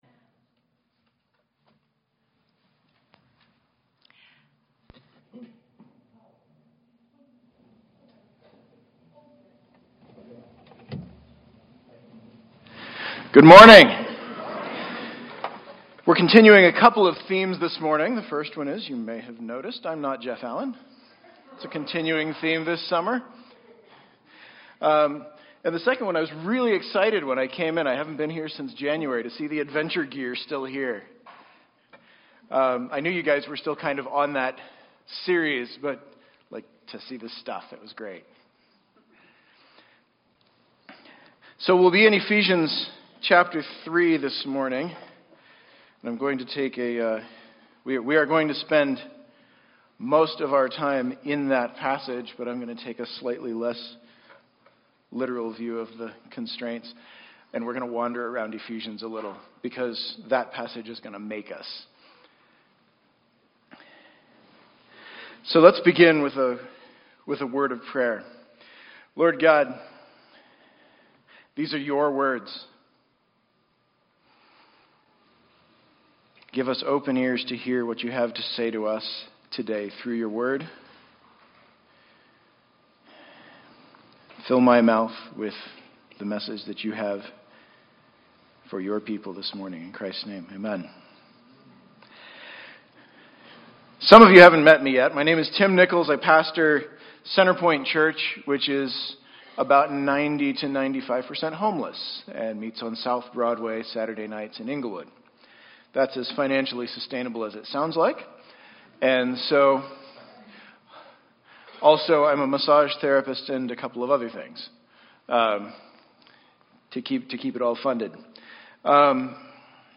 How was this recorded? Had occasion to share a word with Faith Community Church in Littleton a couple weeks ago, on Paul's prayer in Ephesians 3.